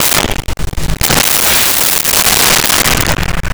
Whip Crack 03
Whip Crack 03.wav